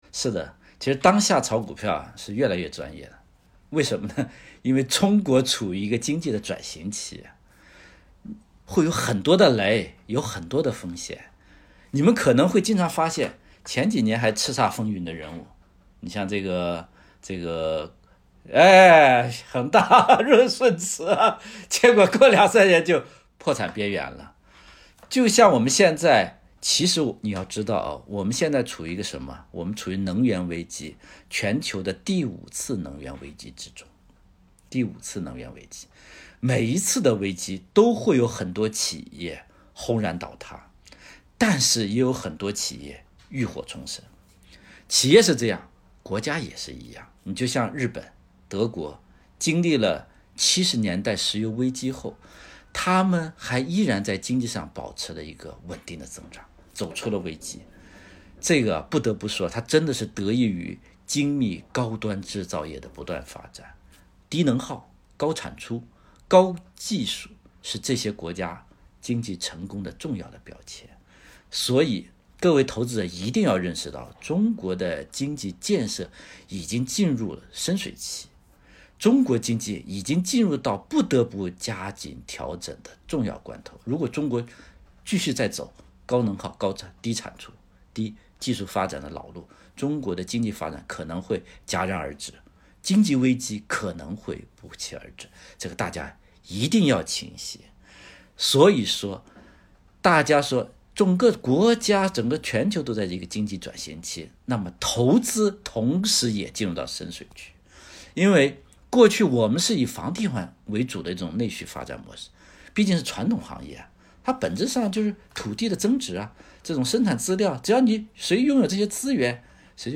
采訪